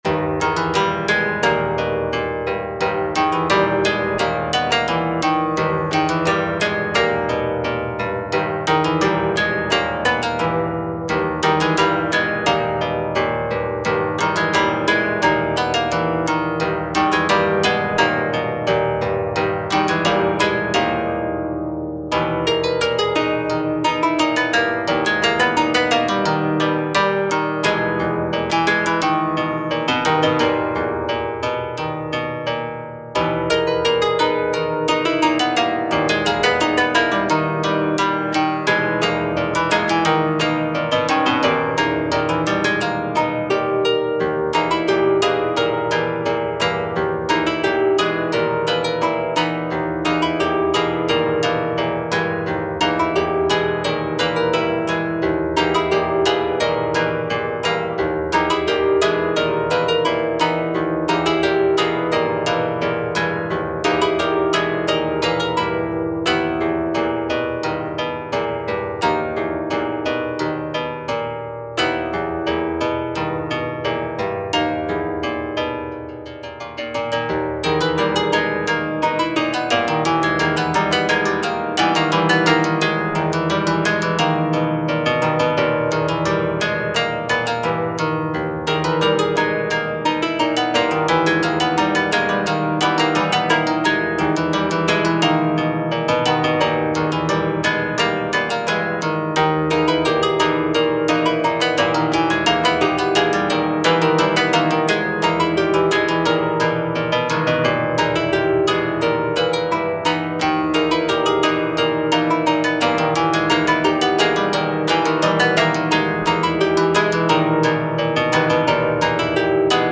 ホラー/怖い 不思議/ミステリアス 不気味/奇妙 和風 寂しい/悲しい 怪しい 暗い 琴 コメント